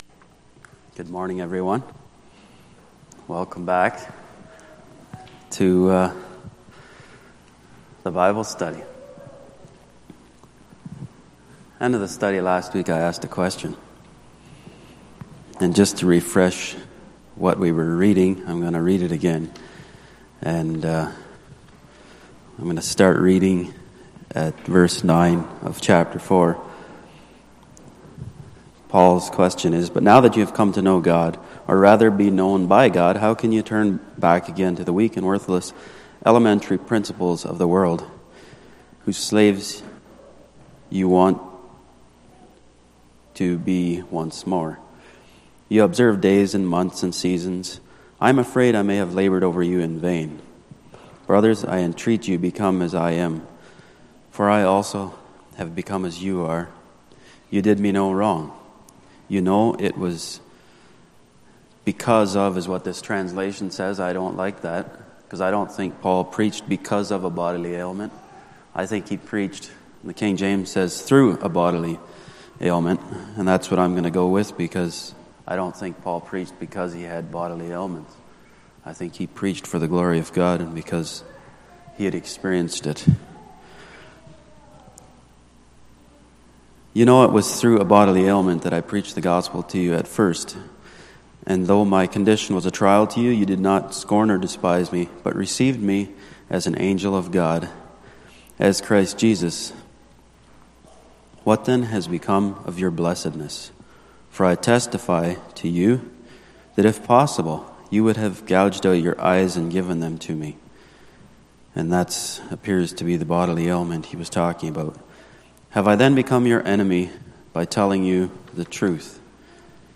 Sunday Morning Bible Study Service Type